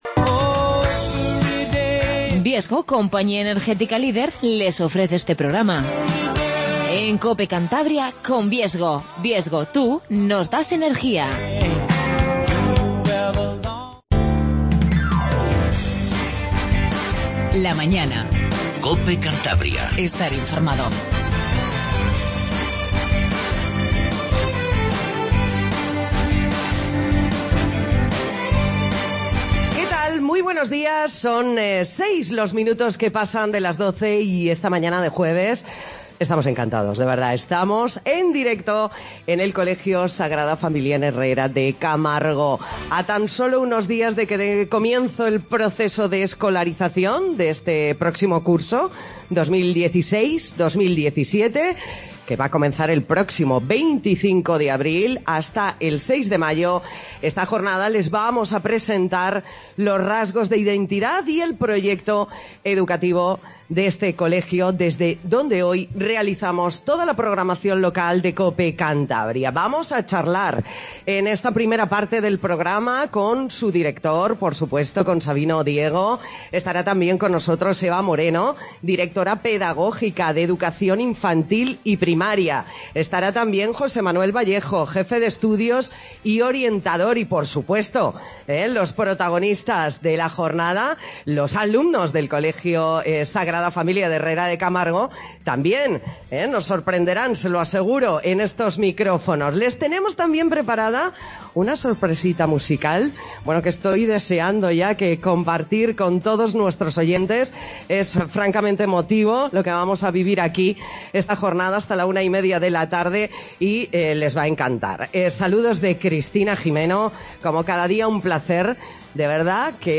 AUDIO: Hoy hacemos el programa desde el Colegio La Sagrada Familia en Herrera de Camargo.